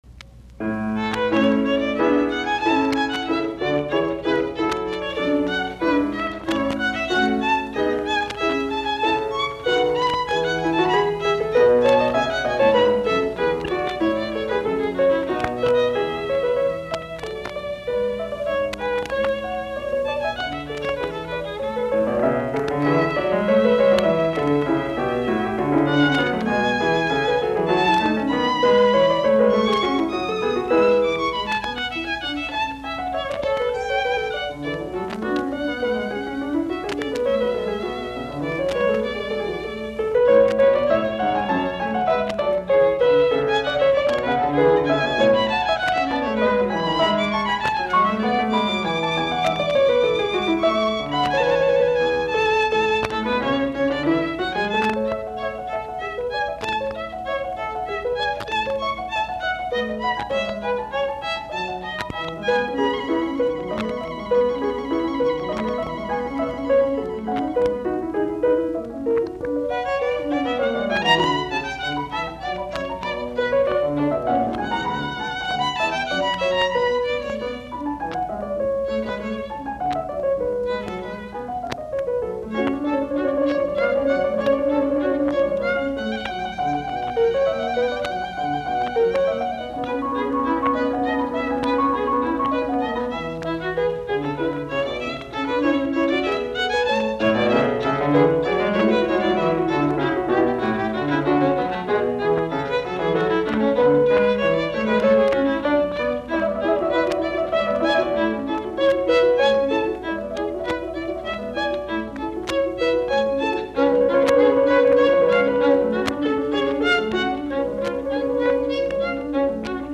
BWV1015, A-duuri; sov. viulu, piano
Soitinnus: Viulu, piano.